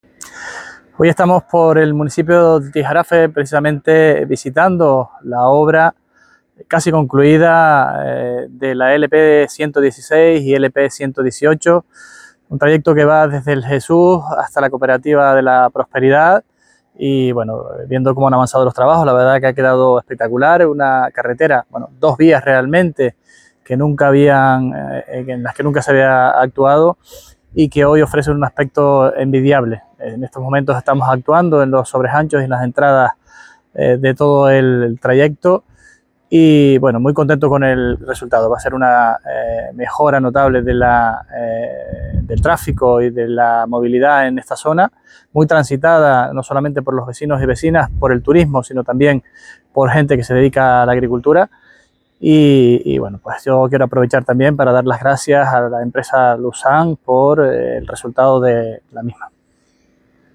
Declaraciones presidente del Cabildo.mp3